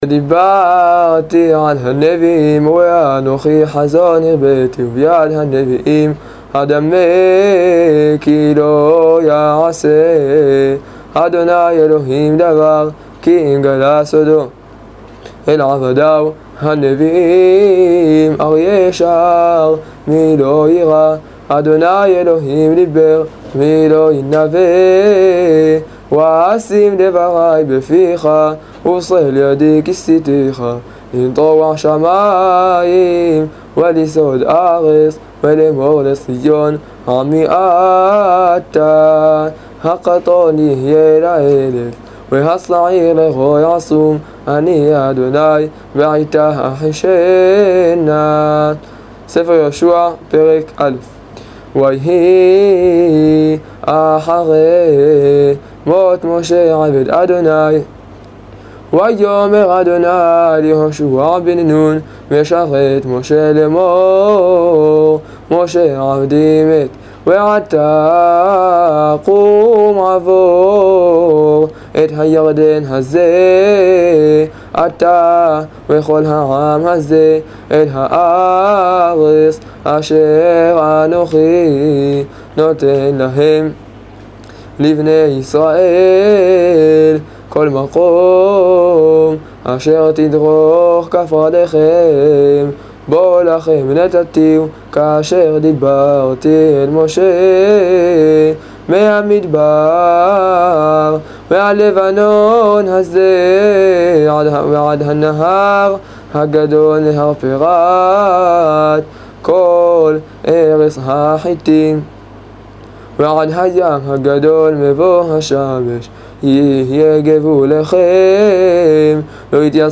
קריאת הנביאים על פי מסורת אבותינו זיע”א